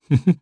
Clause-Vox_Happy1_jp.wav